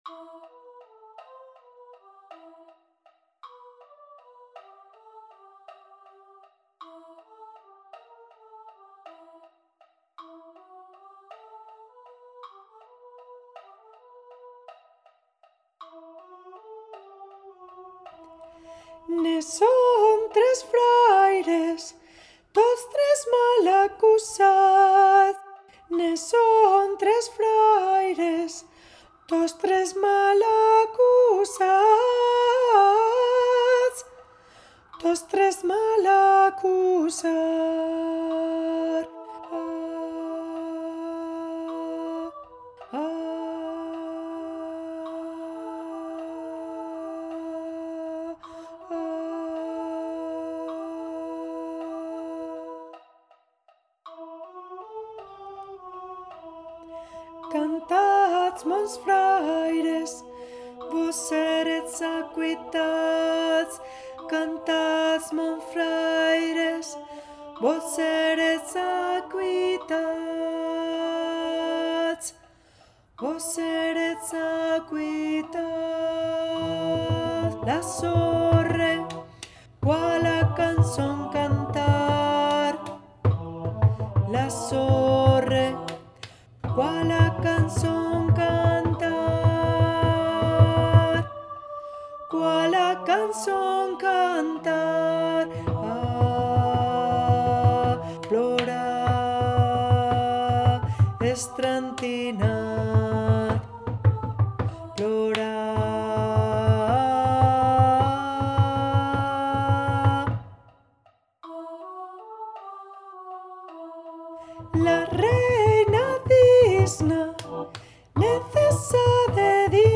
canción tradicional occitana
en arreglo para coro de voces iguales SMA.
coro SMA